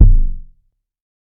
TC3Kick2.wav